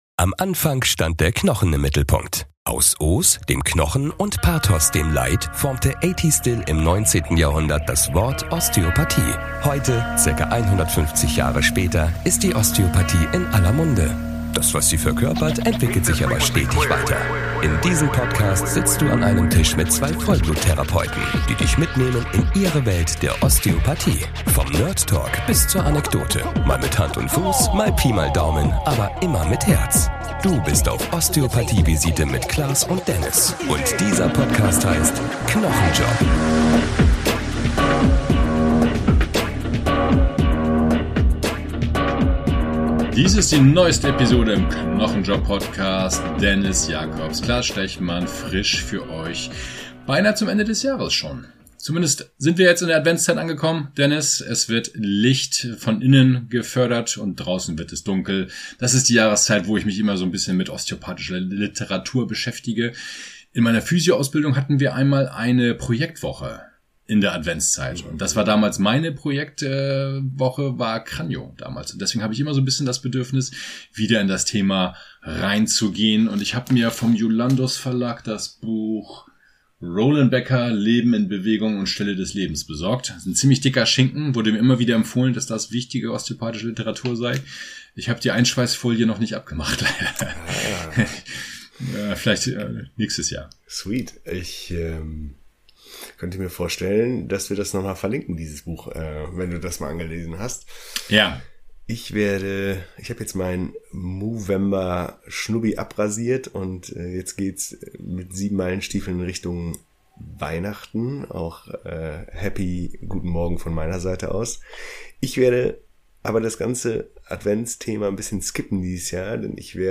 Von Pumpschwengel- über Korbhenkelbewegungen, bis hin zu lymphatischem oder vegetativem Einfluss ist viel dabei, was Dir die Rippen noch einmal näherbringt und dir interessante Verbindungen aufzeigt, die einen weitreichenden Einflauss auf den ganzen Körper haben können. Dazu wie immer Klatsch und Tratsch, Anekdoten, Musik und Spiele, viel Spaß!